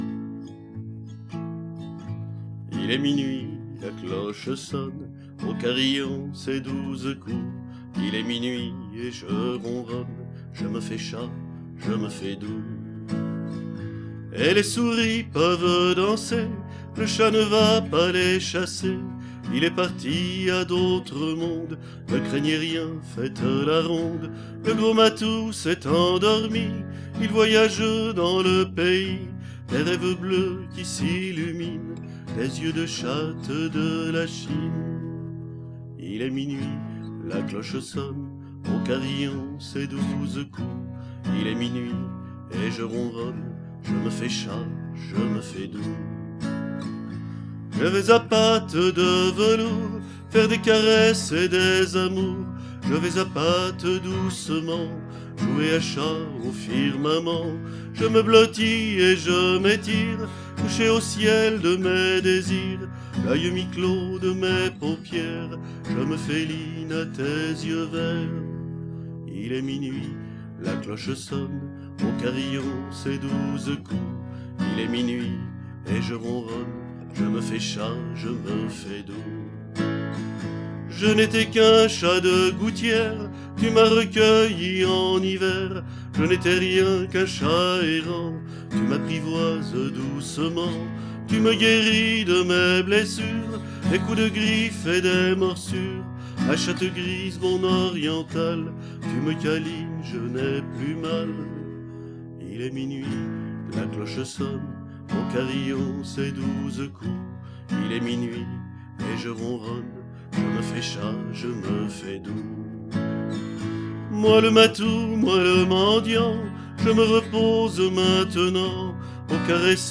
Texte, musique, chant, guitare